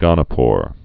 (gŏnə-pôr)